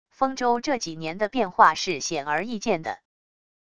丰州这几年的变化是显而易见的wav音频生成系统WAV Audio Player